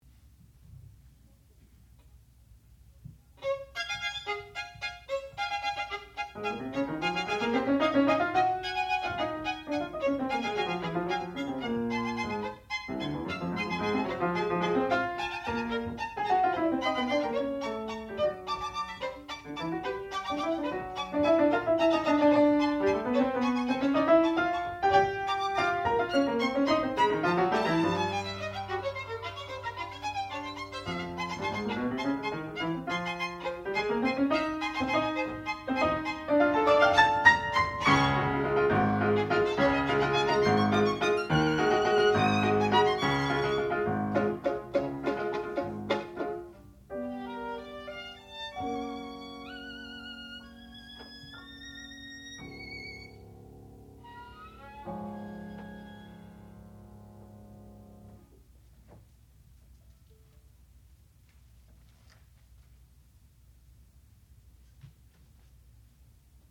classical music
violin
piano
Master's Recital